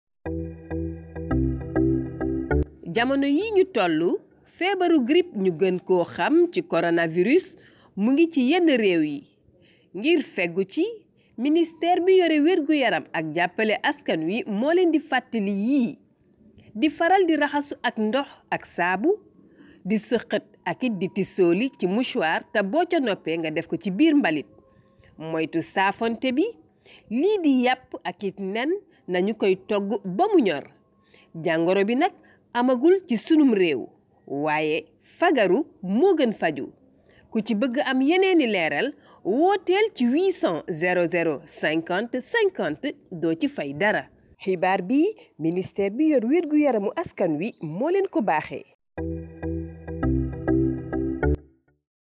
SPOT CORONAVIRUS WOLOF
Spot-coronavirus-radiowolof.mp3